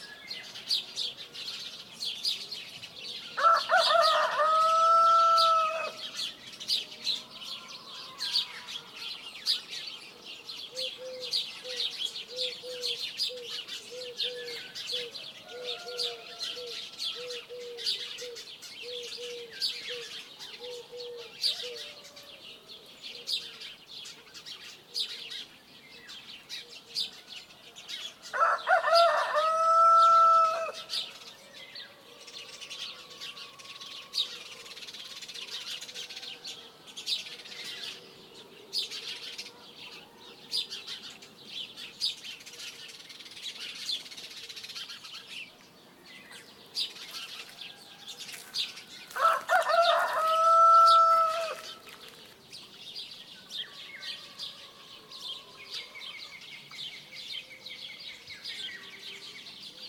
Звуки утра
Любимые утренние звуки в бабушкином селе